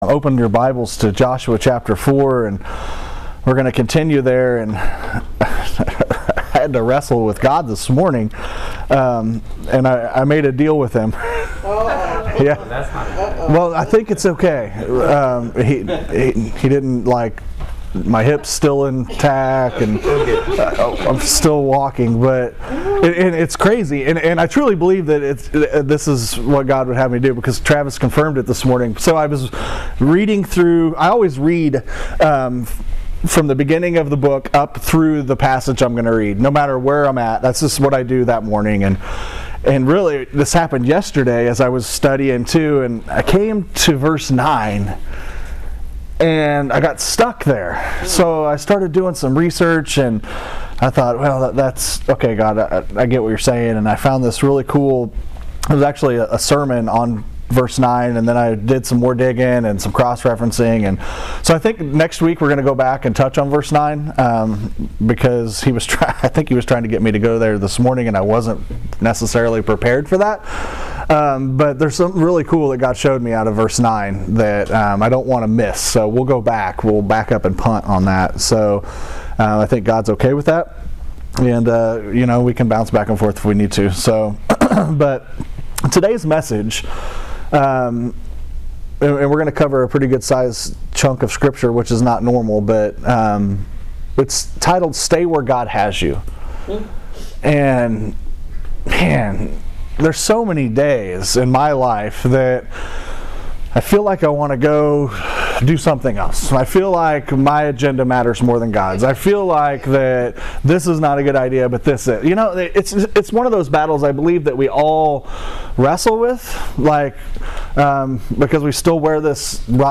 GO Stay Where God Has You July 10, 2022 In this sermon on Joshua chapter 4